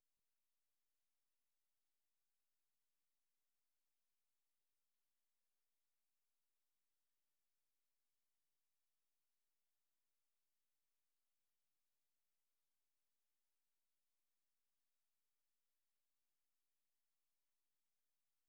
Variations. Представляет собой набор из семи вариаций (6 полных и 1 неполная, с кодой) на тему, написанную в ля мажоре.